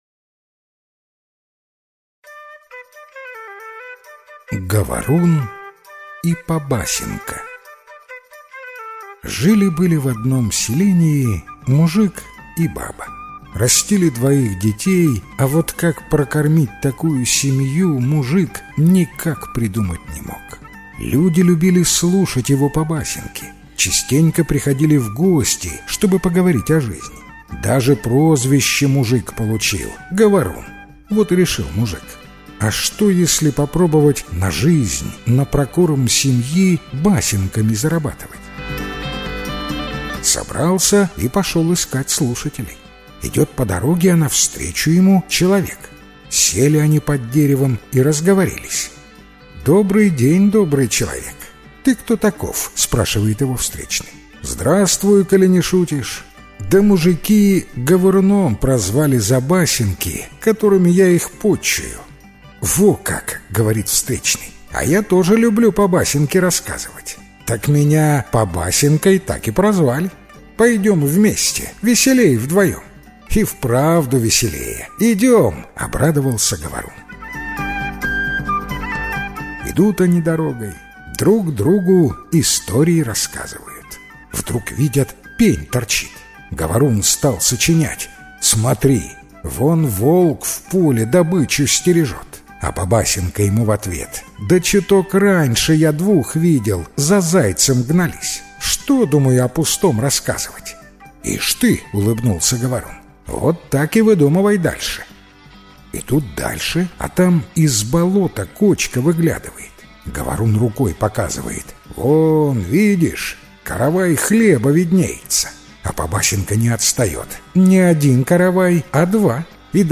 Говорун и Побасенка - белорусская аудиосказка - слушать онлайн